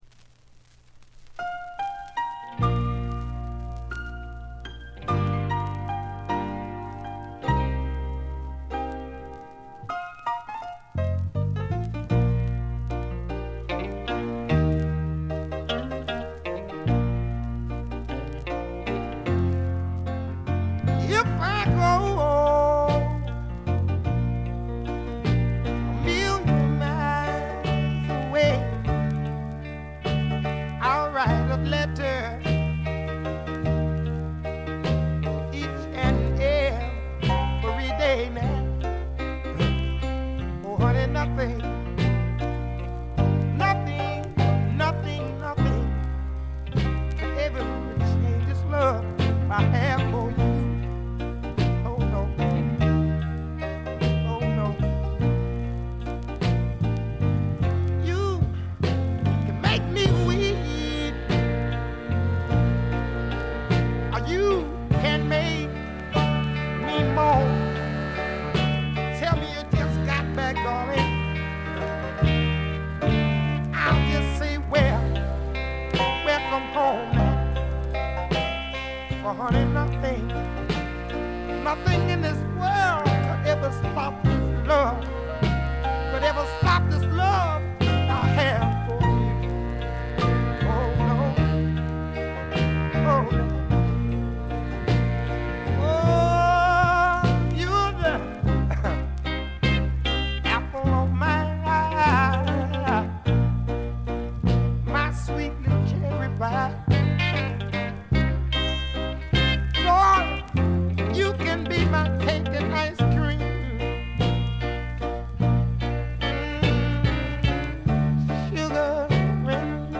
静音部でのバックグラウンドノイズ程度。鑑賞を妨げるようなノイズはありません。
試聴曲は現品からの取り込み音源です。